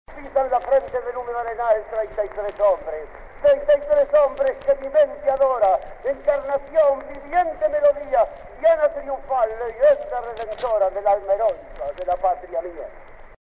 Recitados de Juan Zorrilla de San Martín declamando la Leyenda Patria.
Fueron tomados de un disco de cera que se convirtió a disco de pasata (anterior al vinilo) con gran trabajo de limpieza de ruidos y que en 1975 se publicaron en una edición especial en el Sesquicentenario de los Hechos Históricos de 1825.